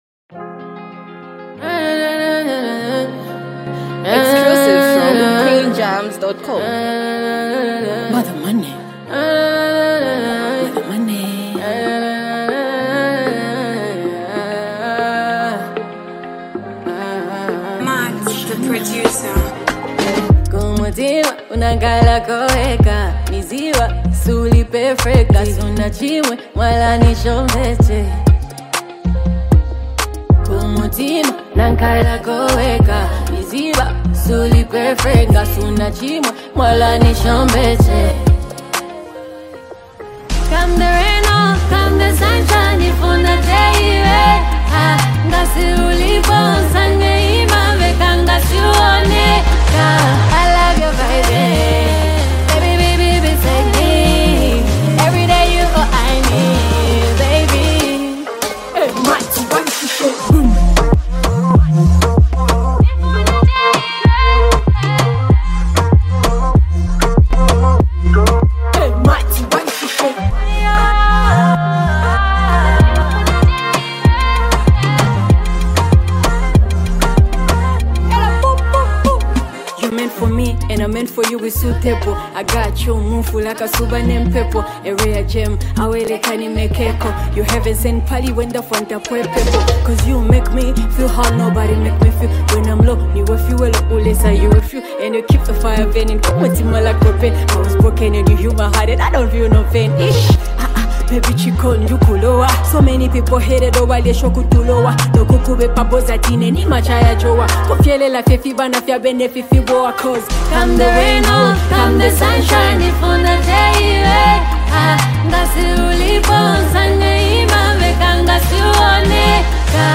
Highly multi talented act and super creative singer